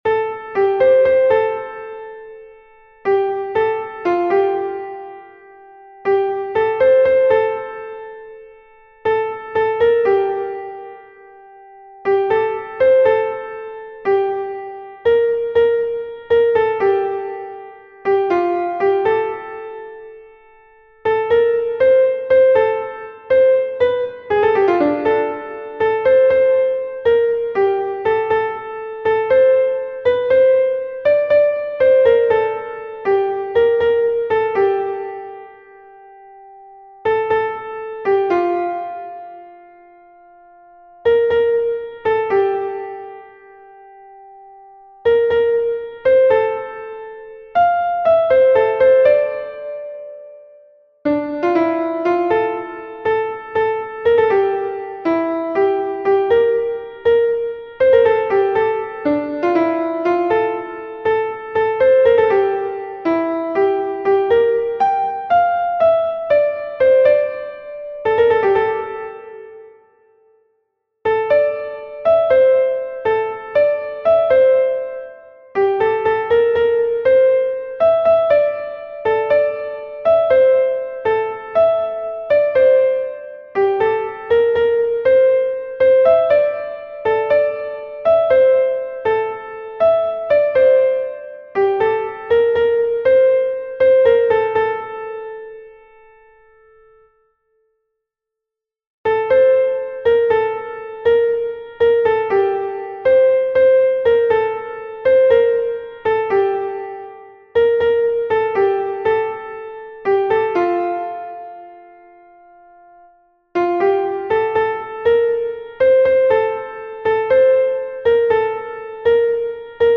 فایل صوتی قطعه که توسط نرم افزار اجرا شده